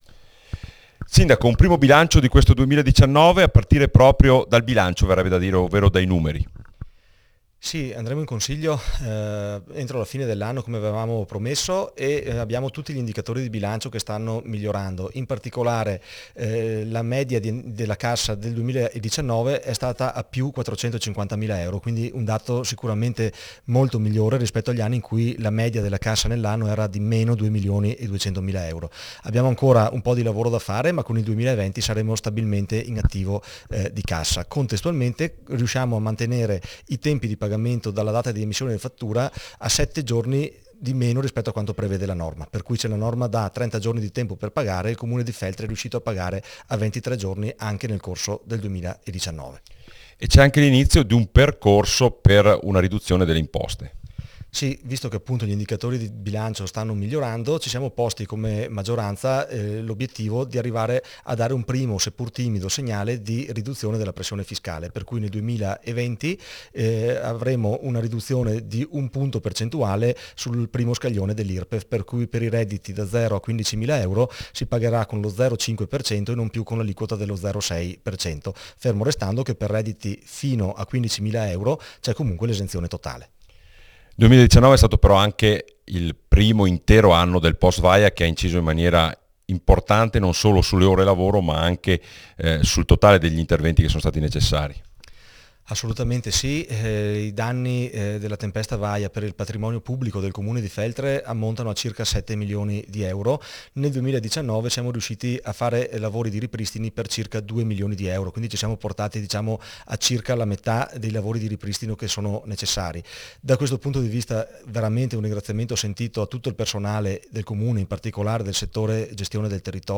FELTRE Il sindaco di Feltre ha incontrato i giornalisti per un bilancio dell’attività del 2019. Ai microfoni di Radio Più, introdotto dal giornalista